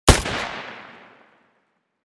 PistolShot03.wav